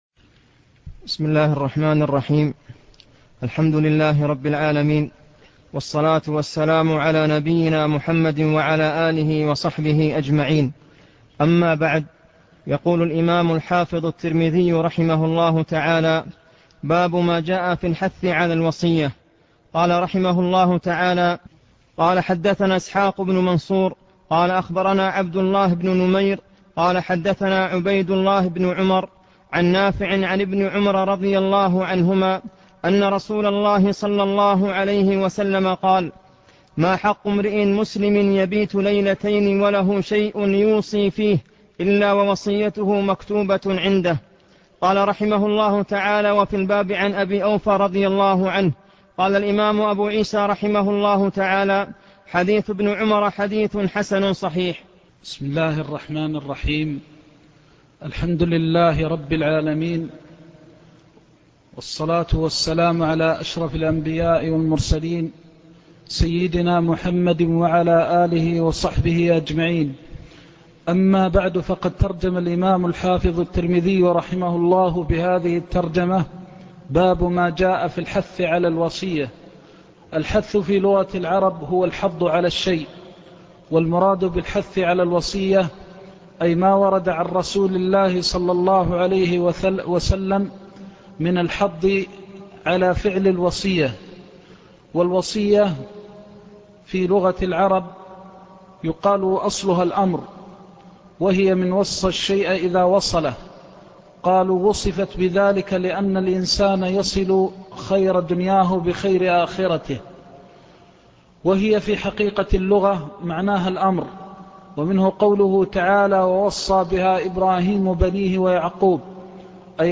كتاب الجنائز الدرس الرابع باب مَا جَاءَ فِي الْحَثِّ عَلَى الْوَصِيَّةِ